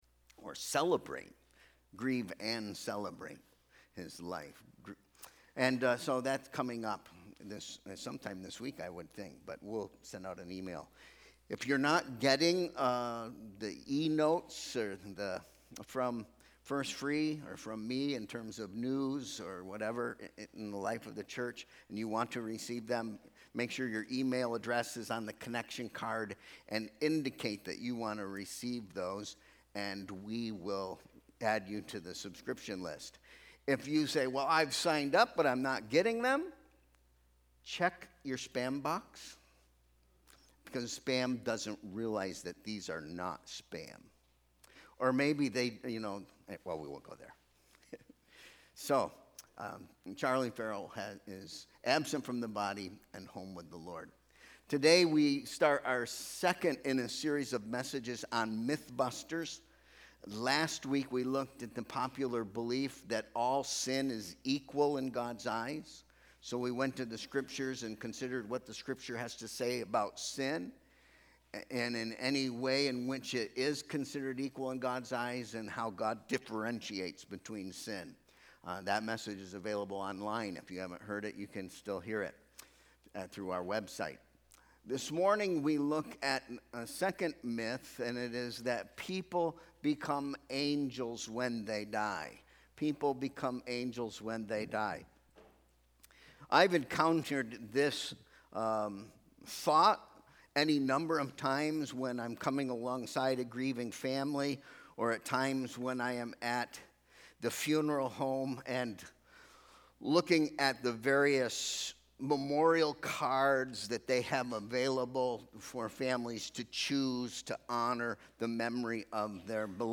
Sermon Question: What happens to people when they die?